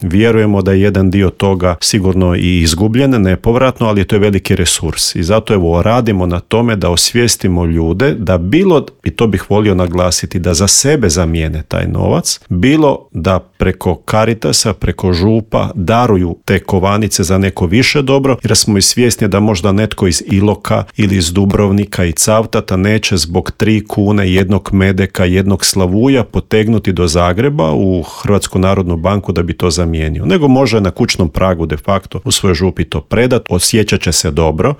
O projektu smo u Intervjuu MS-a razgovarali